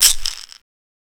Perc (TM88).wav